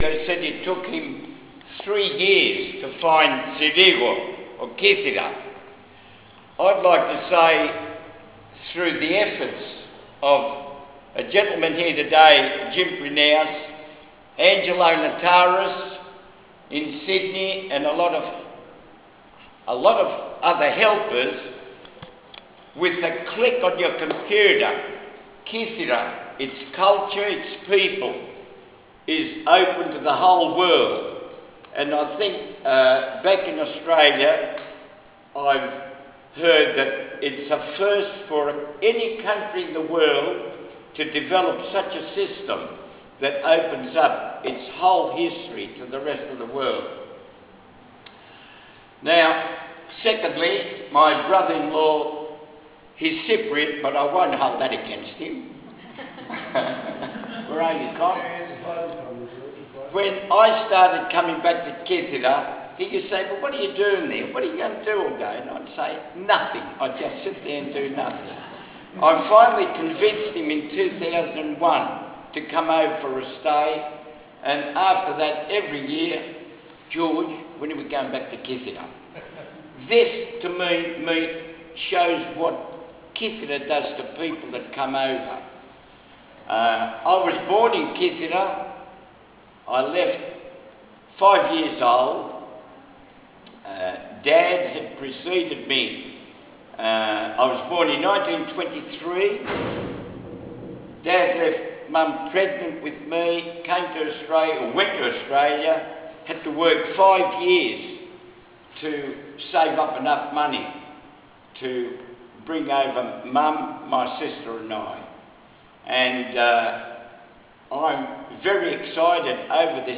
Here are some of the speeches from the Discover Your Roots Day on Kythera in August 2006.